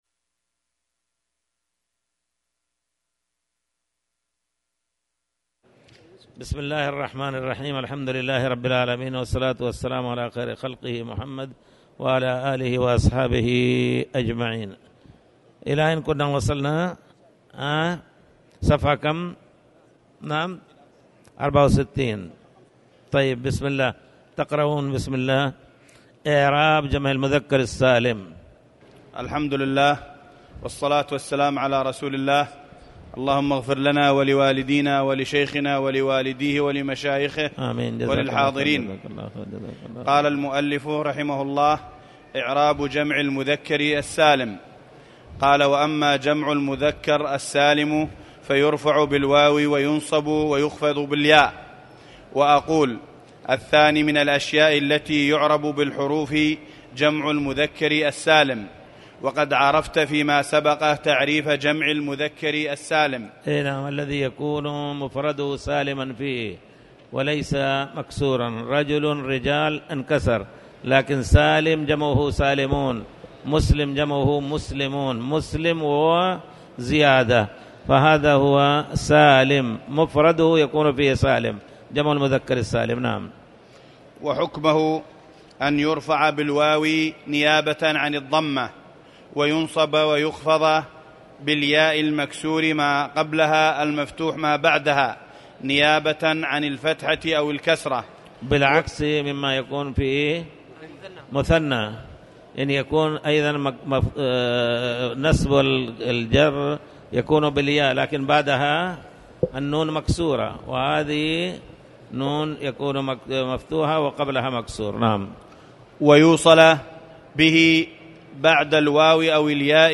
تاريخ النشر ٥ شعبان ١٤٣٩ هـ المكان: المسجد الحرام الشيخ